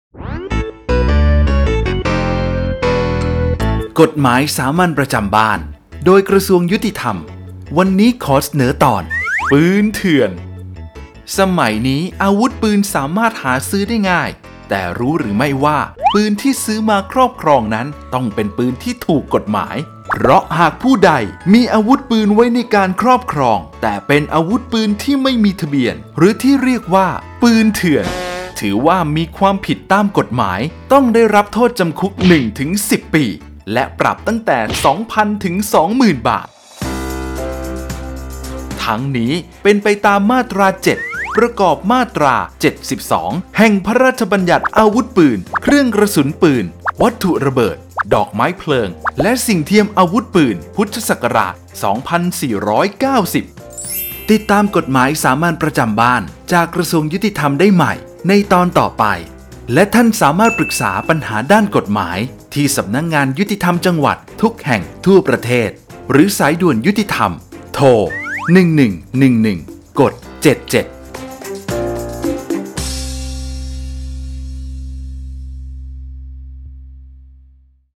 กฎหมายสามัญประจำบ้าน ฉบับภาษาท้องถิ่น ภาคกลาง ตอนปืนเถื่อน
ลักษณะของสื่อ :   บรรยาย, คลิปเสียง